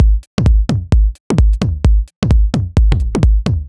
Balear 130bpm.wav